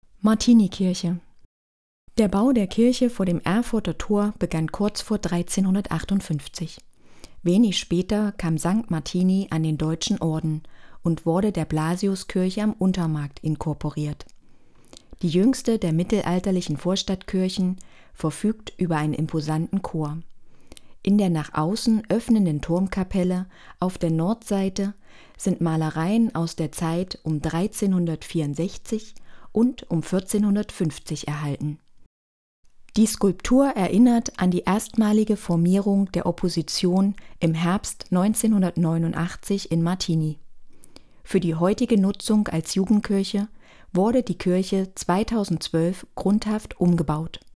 Beschreibung zur Martinikirche